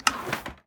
Minecraft Version Minecraft Version latest Latest Release | Latest Snapshot latest / assets / minecraft / sounds / ui / loom / select_pattern5.ogg Compare With Compare With Latest Release | Latest Snapshot